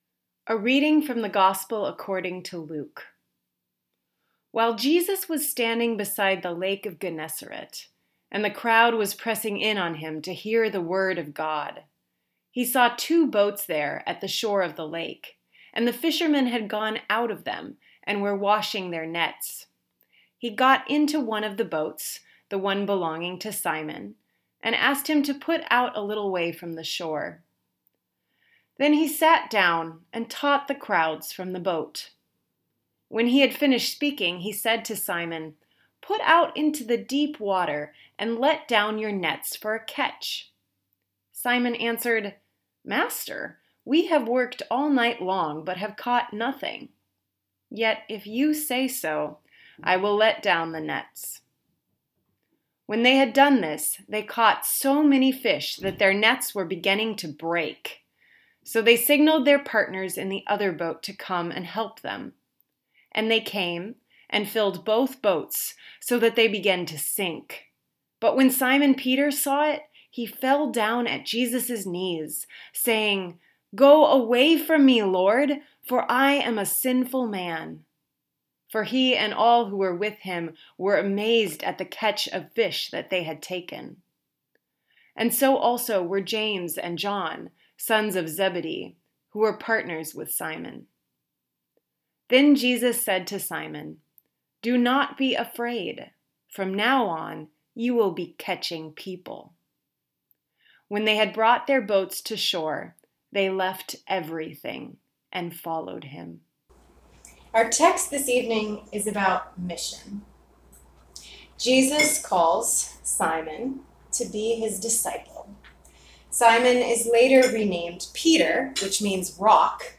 Feb 10, 2019 Sermon
Let’s talk about mission! Here’s my sermon from the fifth Sunday after Epiphany: